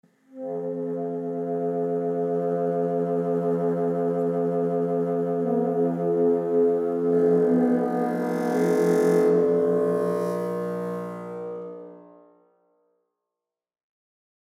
Zahlreiche Effektsounds runden das Angebot speziell für die Filmmusik ab, hier am Beispiel der Fagotte:
Aufwärmübungen, garniert mit Nebengeräuschen: